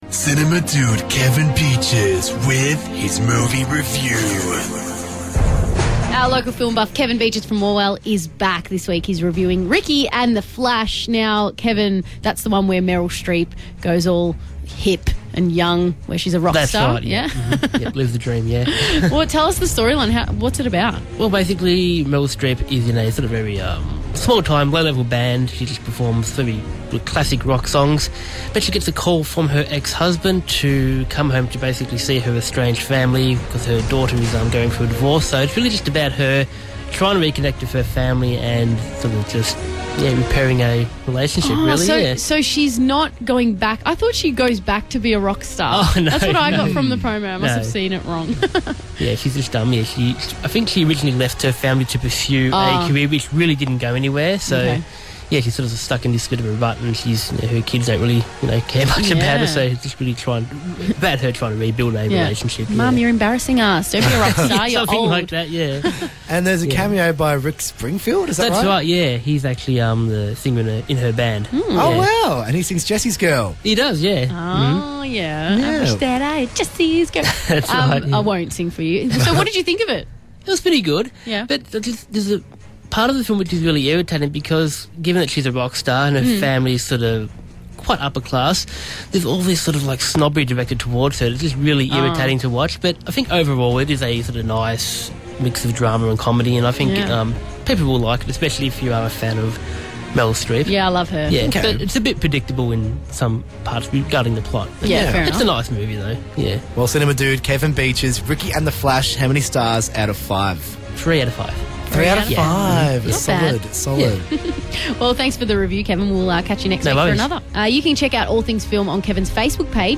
Review: Ricki and the Flash (2015)